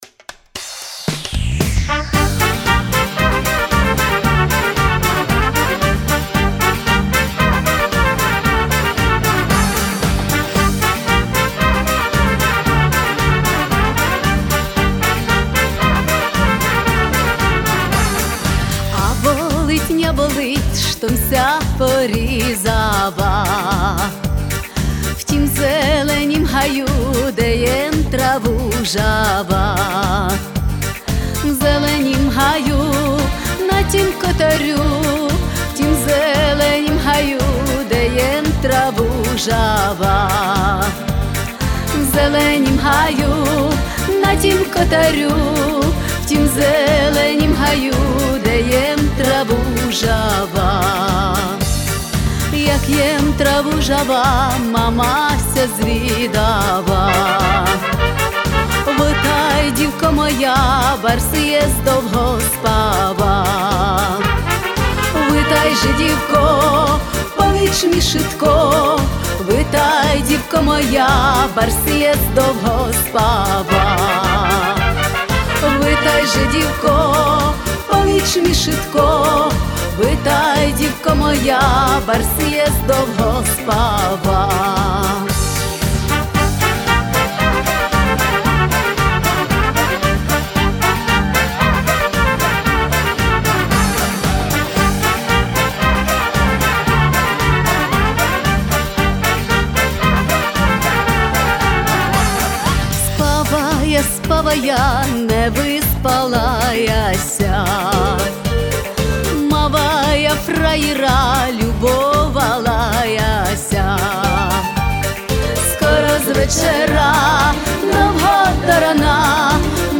Плюсовий запис
Це лемківська пісня